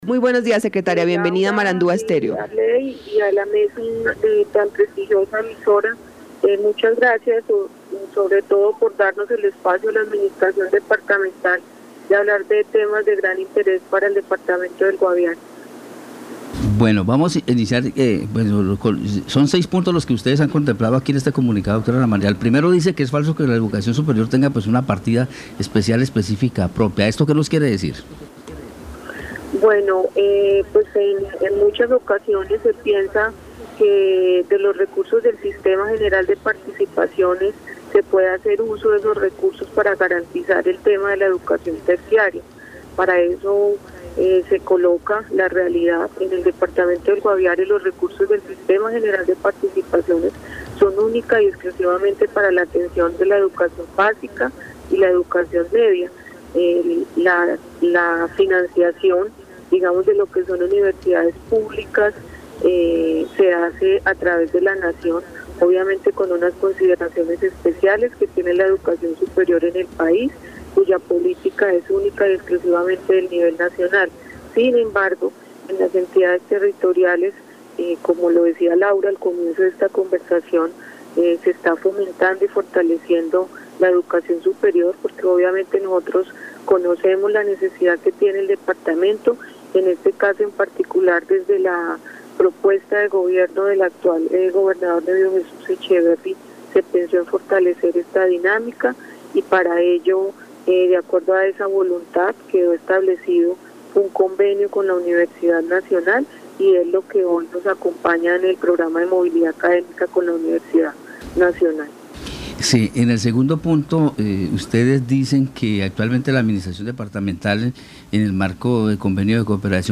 Escuche a Ana María Martínez, secretaria de Educación del Guaviare.